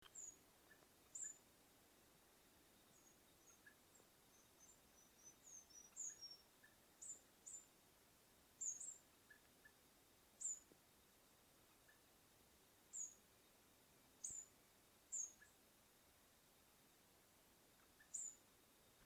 Viudita Coluda (Muscipipra vetula)
Lifer! Bien observada, vocalizando y volando (cazando insectos) Viudita larga y con cola furcada, gris y negra, observada en el punto donde habitualmente se la ve.
Nombre en inglés: Shear-tailed Grey Tyrant
Localidad o área protegida: Parque Provincial Caá Yarí
Condición: Silvestre
Certeza: Fotografiada, Vocalización Grabada